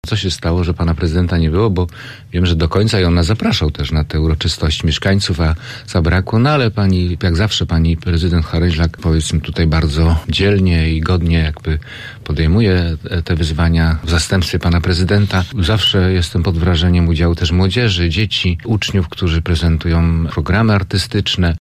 Radny PiS-u komentuje organizację miejskich uroczystości na 100-lecie odzyskania przez Polskę niepodległości.